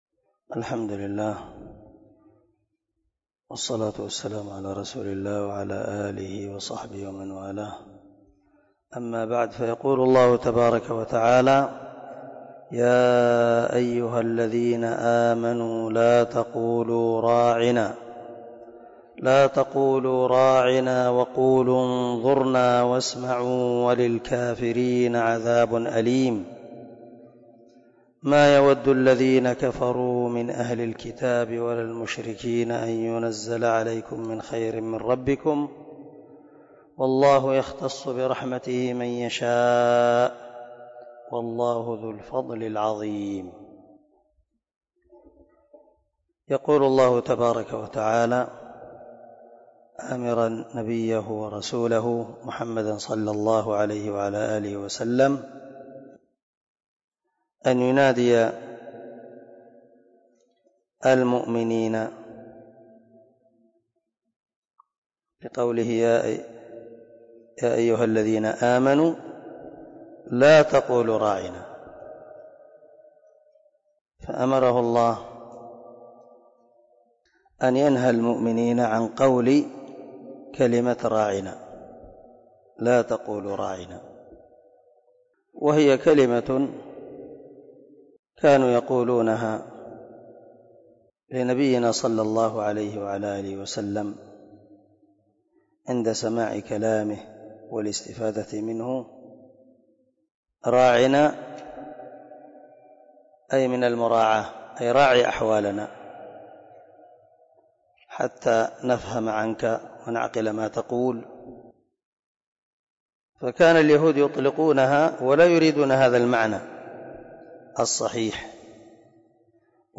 043الدرس 33 تفسير آية ( 104 – 105 ) من سورة البقرة من تفسير القران الكريم مع قراءة لتفسير السعدي